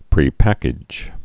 (prē-păkĭj)